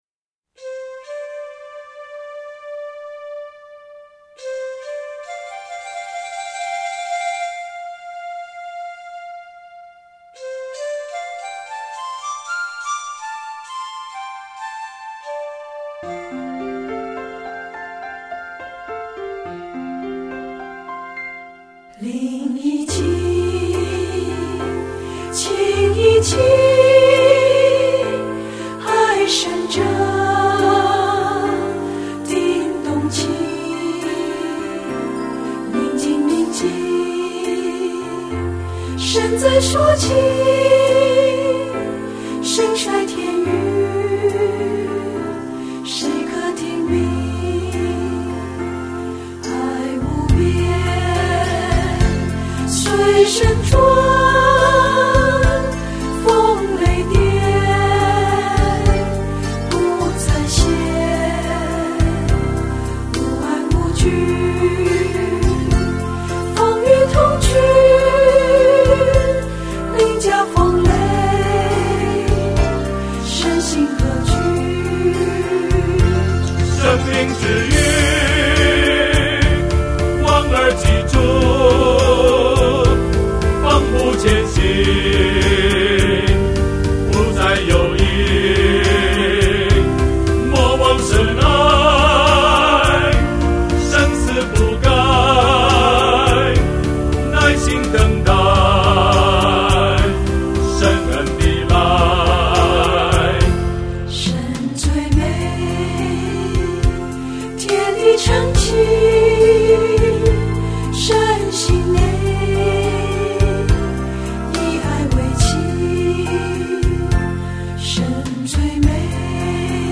F調4/4